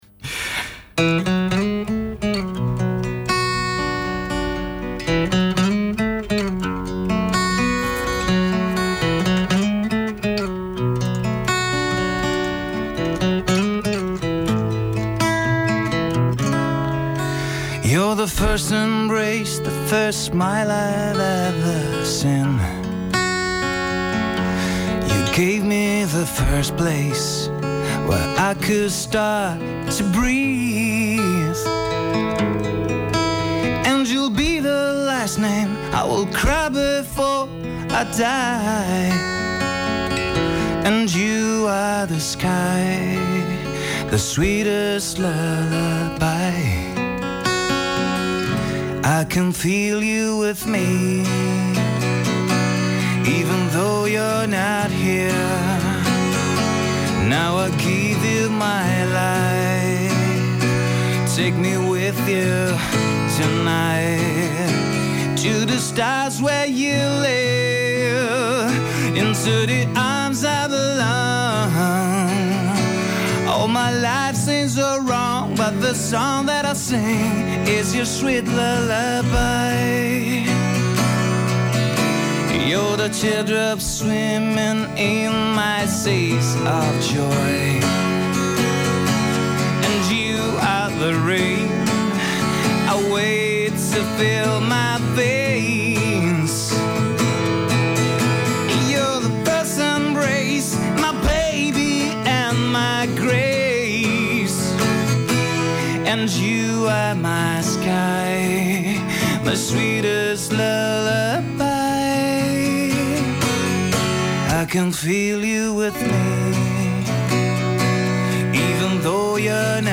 chitarra e voce, registrata dal vivo